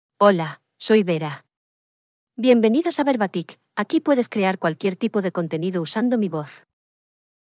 VeraFemale Spanish AI voice
Vera is a female AI voice for Spanish (Spain).
Voice sample
Listen to Vera's female Spanish voice.
Female
Vera delivers clear pronunciation with authentic Spain Spanish intonation, making your content sound professionally produced.